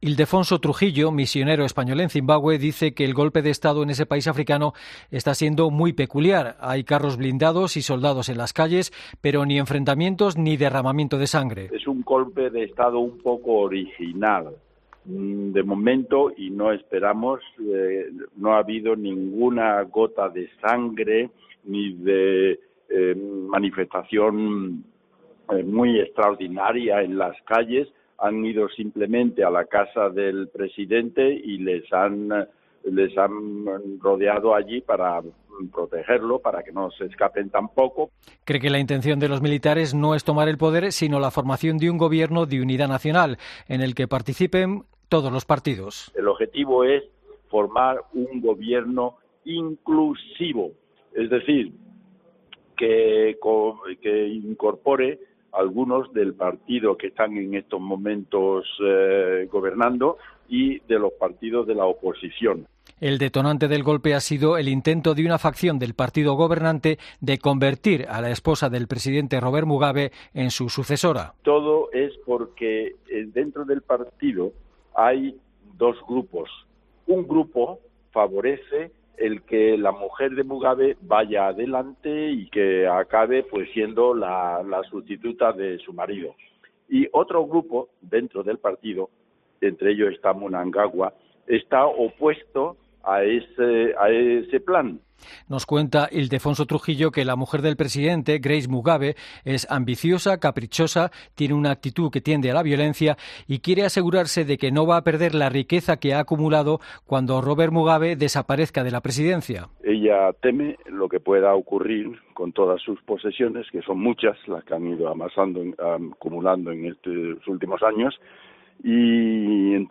Misionero español en Zimbabue: “El golpe de Estado es original, sin enfrentamientos ni sangre"